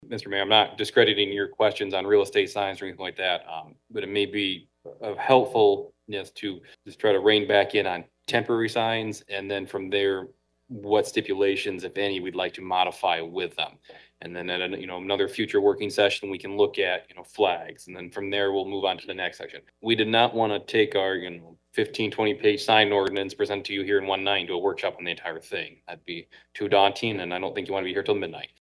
COLDWATER, MI (WTVB) – The Coldwater Planning Commission discussed possible changes regarding the city’s Zoning Ordinance for temporary and downtown signs during their Monday night meeting